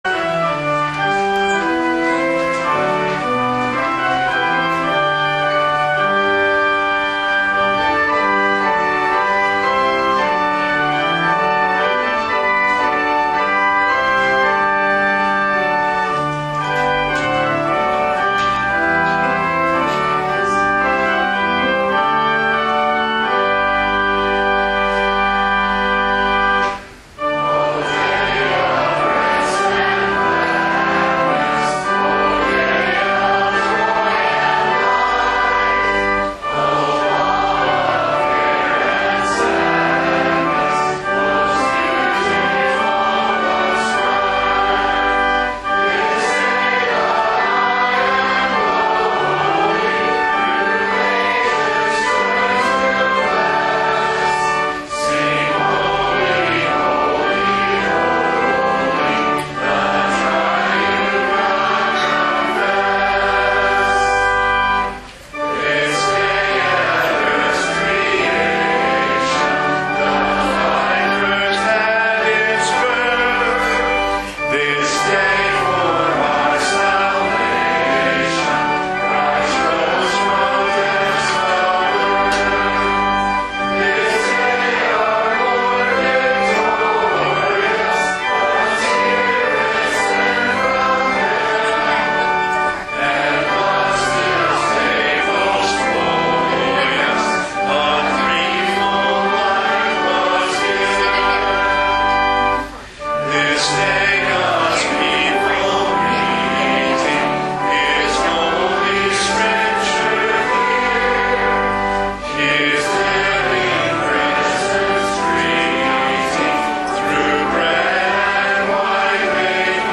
Pastor’s Adult Instruction Class